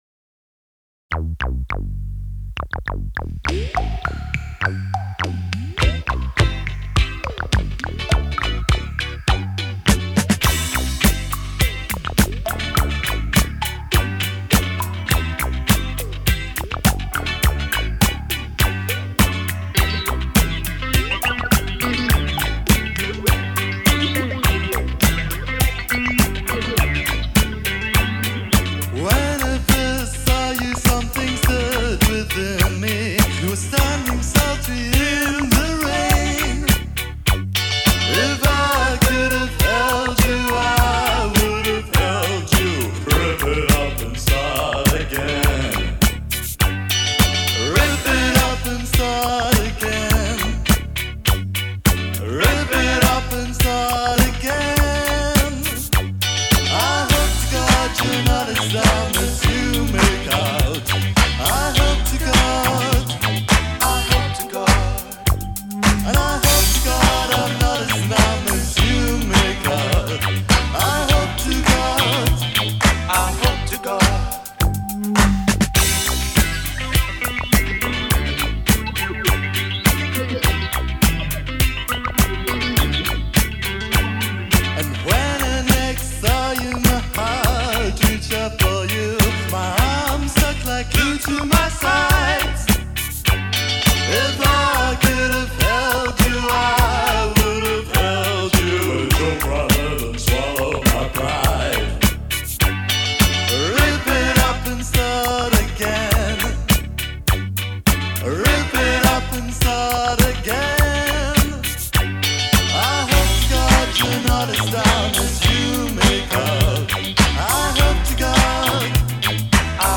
The sweet sound of your synthesized bassline
Is that crooning I hear over your quirky disco sounds?